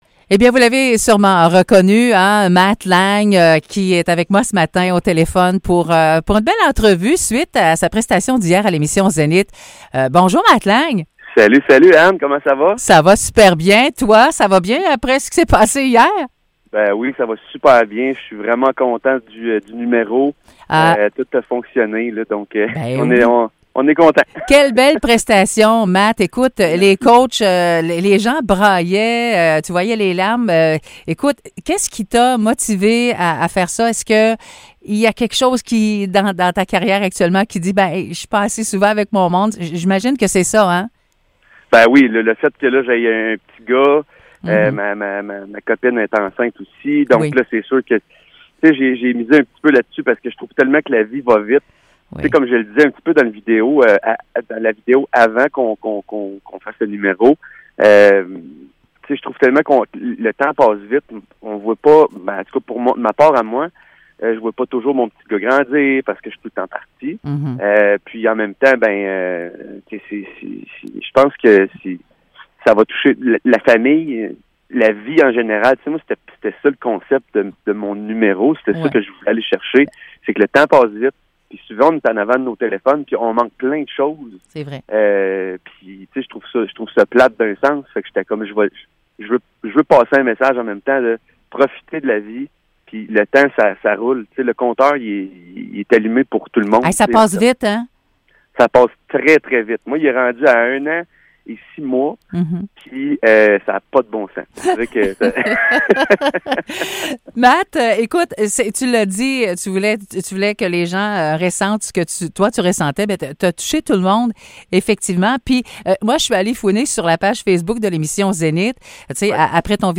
Entrevue avec Matt Lang à la suite de son passage à Zénith